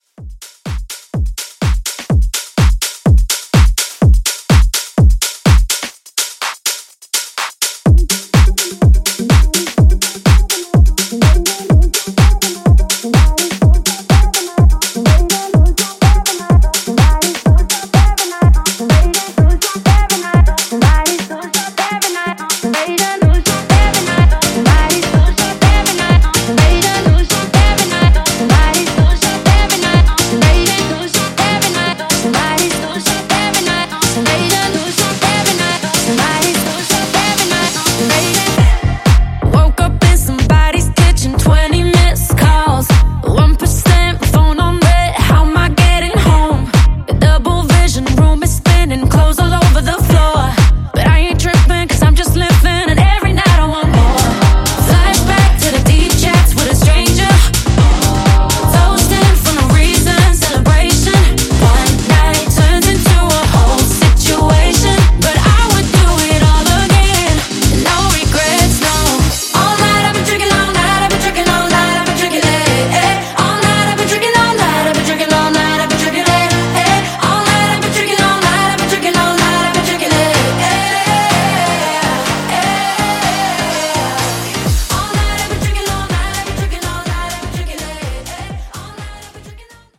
Genres: RE-DRUM , TOP40 Version: Clean BPM: 125 Time